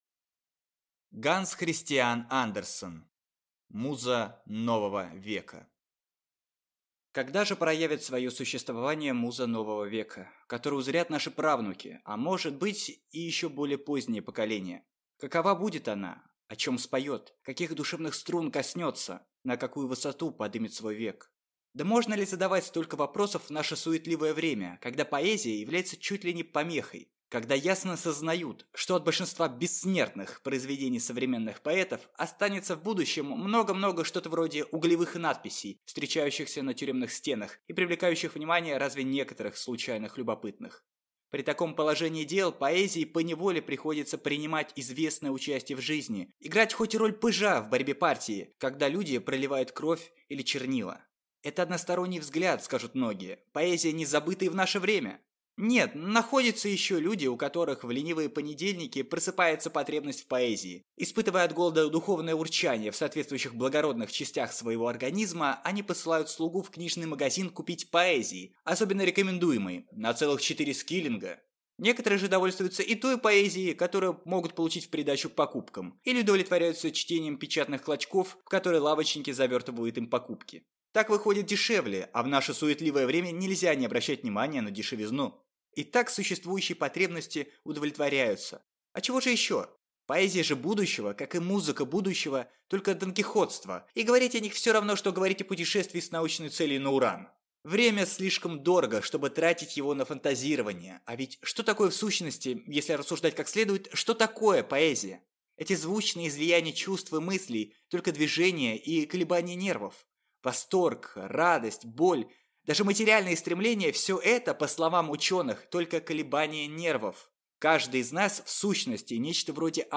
Аудиокнига Муза нового века | Библиотека аудиокниг